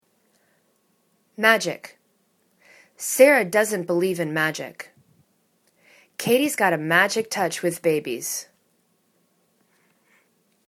magic  /'madjik/